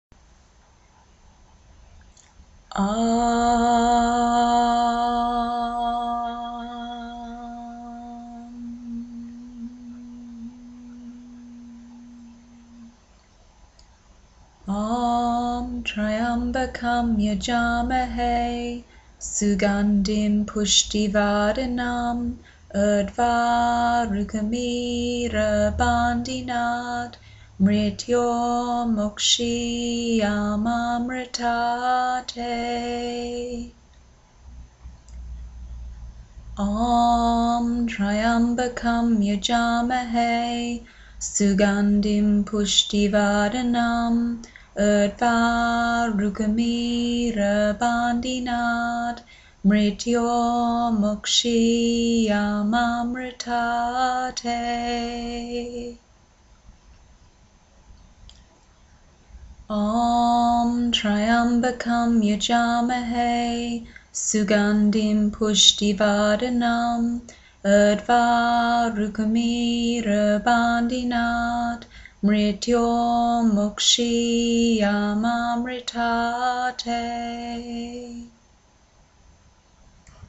Mantra
The passages that we chant at yogaphysio are short passages taken from ancient philosophical texts called the Upanishads.